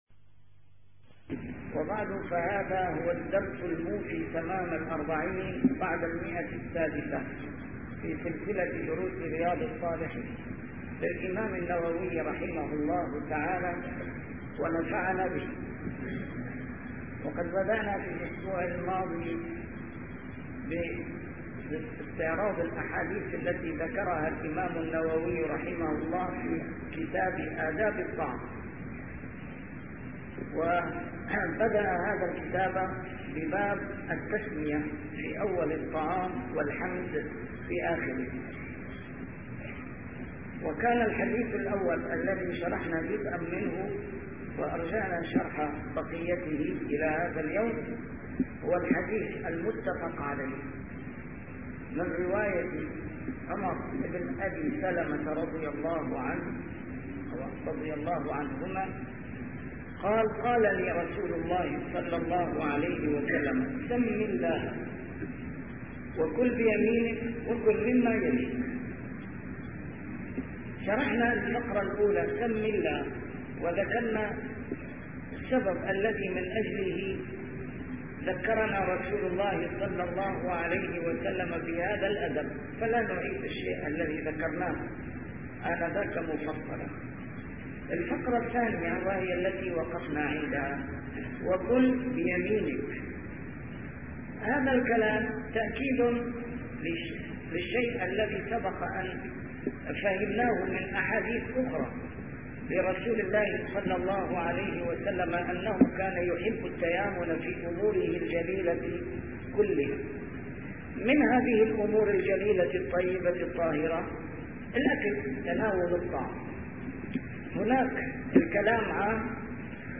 A MARTYR SCHOLAR: IMAM MUHAMMAD SAEED RAMADAN AL-BOUTI - الدروس العلمية - شرح كتاب رياض الصالحين - 640- شرح رياض الصالحين: التسمية في أول الطعام والحمد في آخره